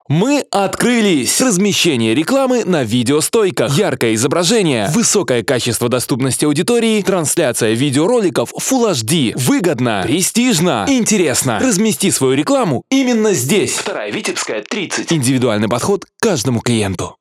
Муж, Другая/Средний
RME Babyface pro, LONG, DBX, Digilab, Neumann TLM 103, 023 Bomblet, ARK 87, Oktava MK-105, sE 2200